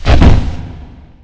bullet_hit_player.wav